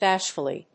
音節básh・ful・ly 発音記号・読み方
/‐fəli(米国英語)/